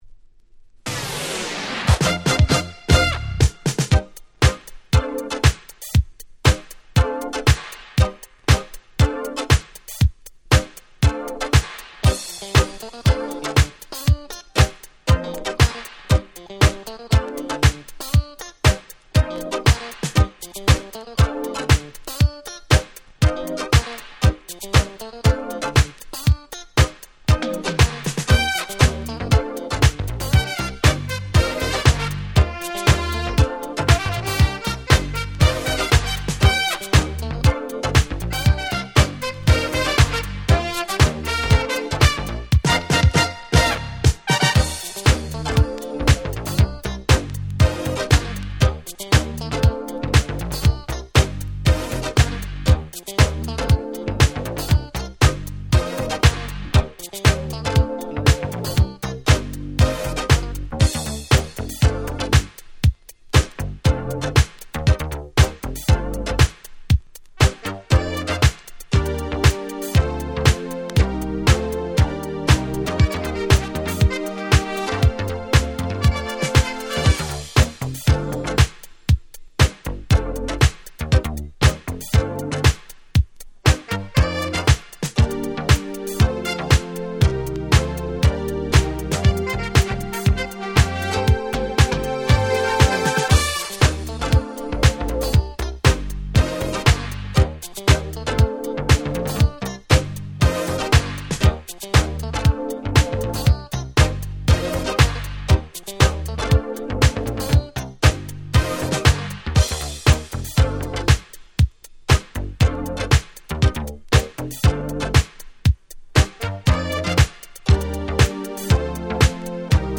83' Nice UK Disco / Boogie !!
これがまた超絶Jazzyで歌入りとはまた別の使い方の出来る最高のInstrumental Discoなんです！！
特に後半のPianoパートは圧巻！！